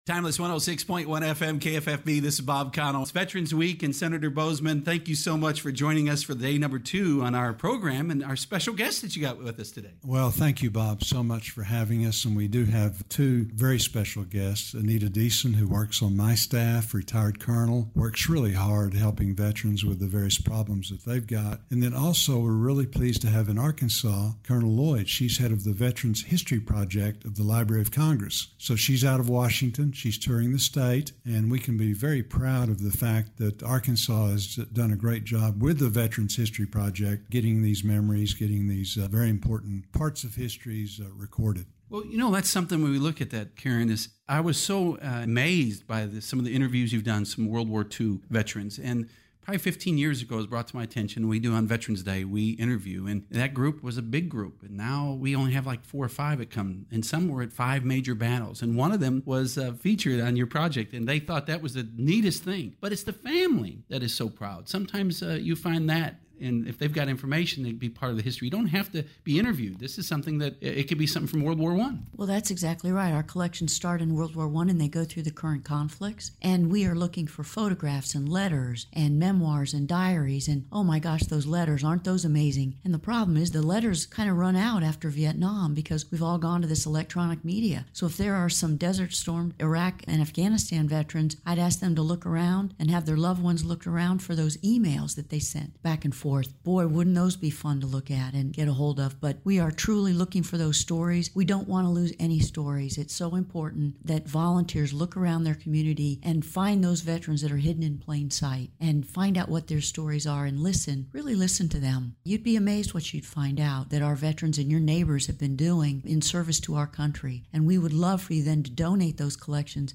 interview program